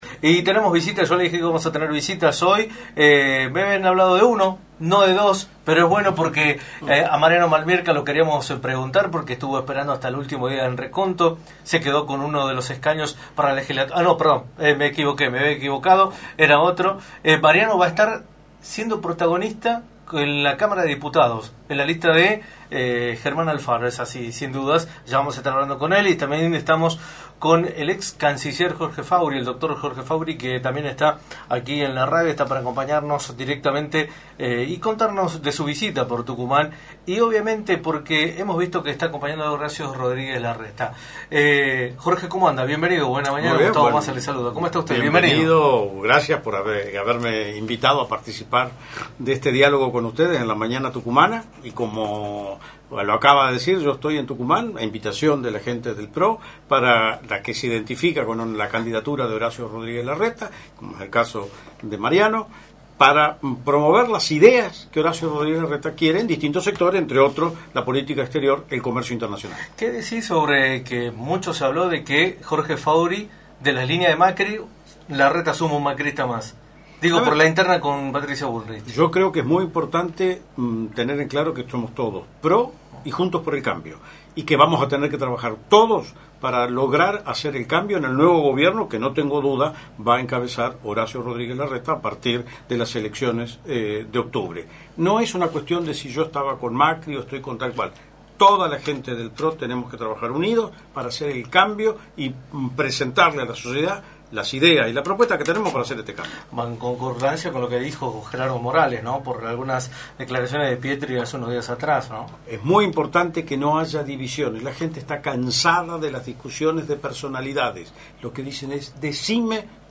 Jorge Faurie, ex Canciller de la gestión del ex Presidente Mauricio Macri, visitó los estudios de Radio del Plata Tucumán, por la 93.9, para analizar el escenario político nacional, en la previa de las elecciones PASO que se llevarán a cabo en un mes.